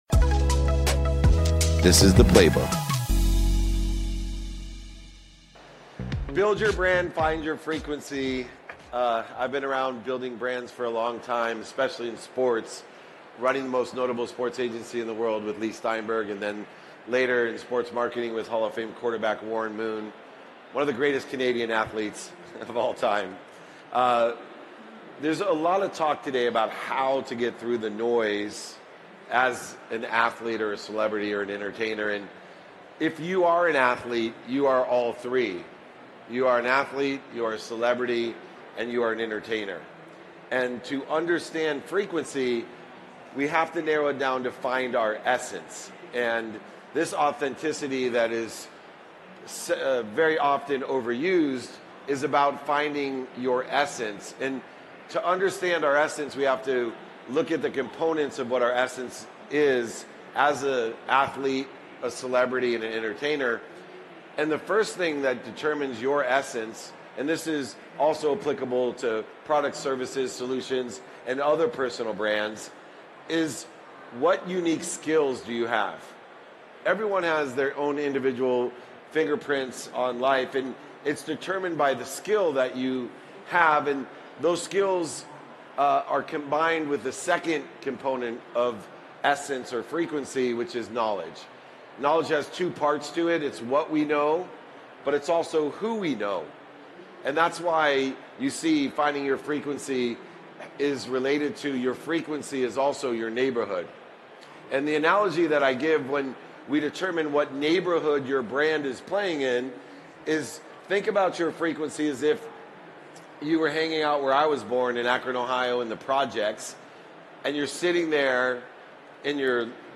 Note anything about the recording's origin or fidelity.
Today's episode is from June 2023 at Collision Conference in Toronto. The keynote focuses on building a solid personal brand and capturing your essence.